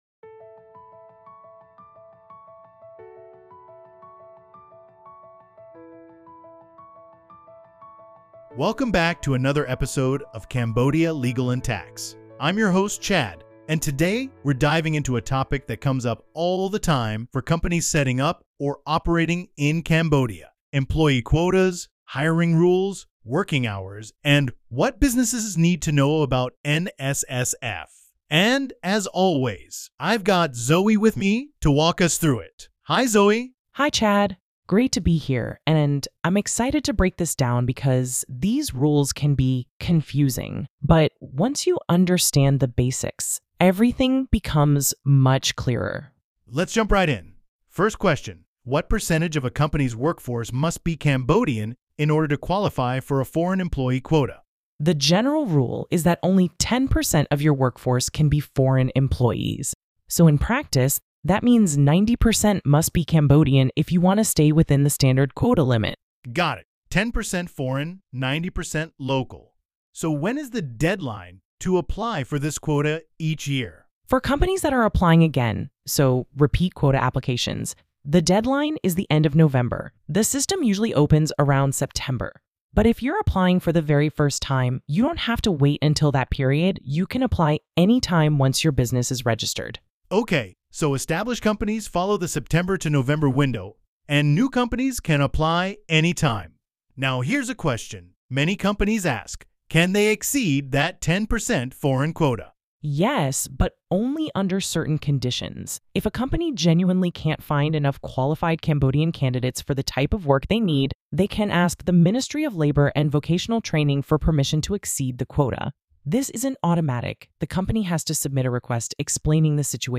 Discussion on Frequently Asked Questions Related to the Labor Environment in Cambodia